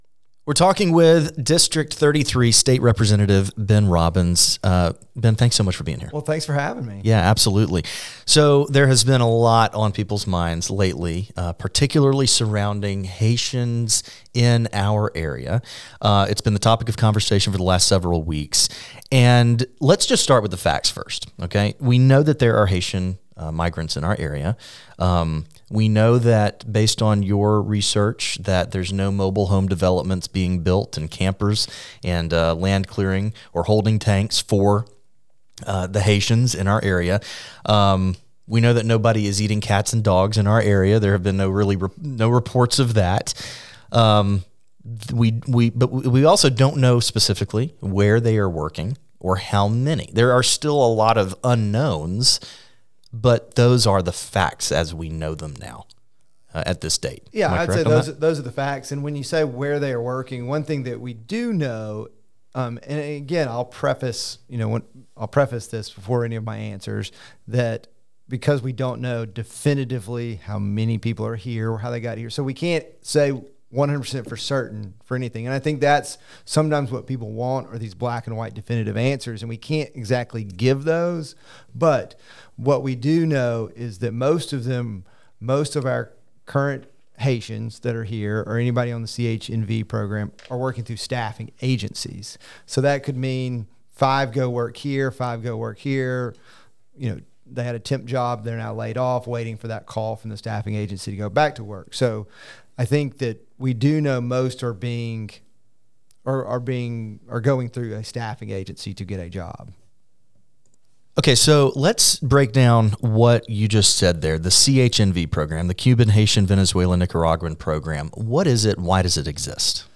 [EXCLUSIVE] State Representative Ben Robbins talks about Haitian migrants in Sylacauga